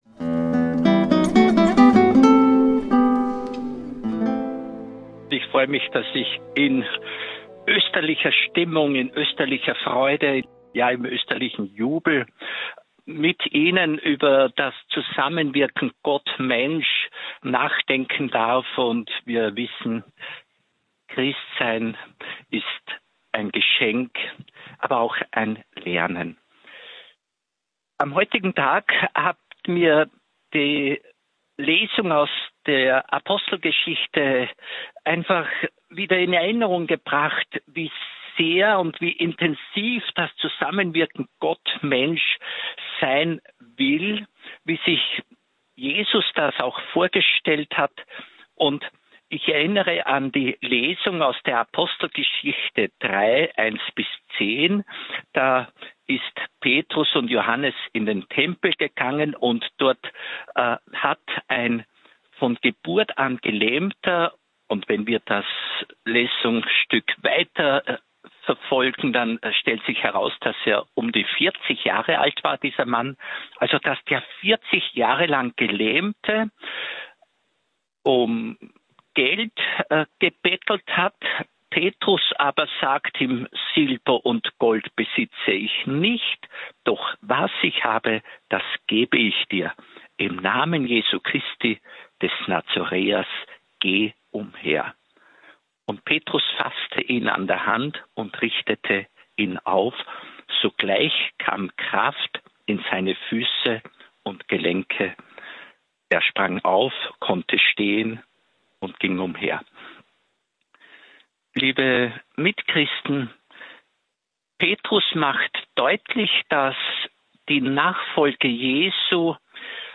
(Aufzeichnung der Radio Maria Sendung vom 6.3.2024) Mehr